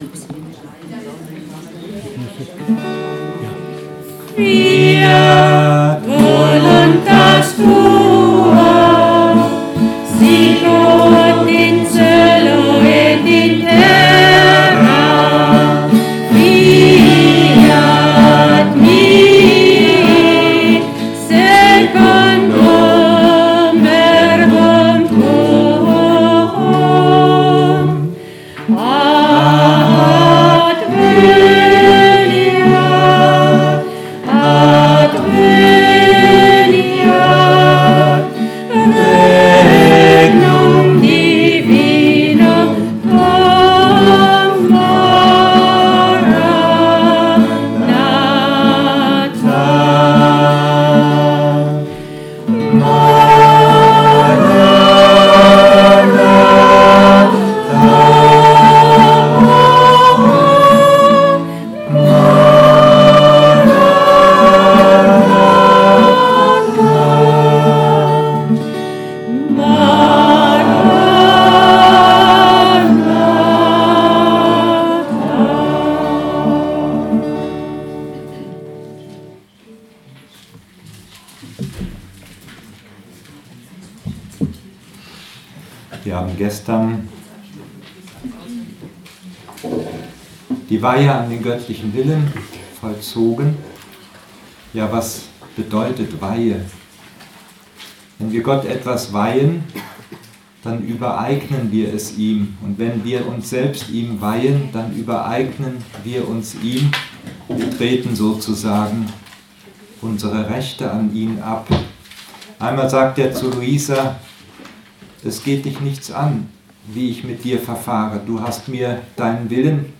Seminar in Heroldsbach - 3. bis 5. Mai 2019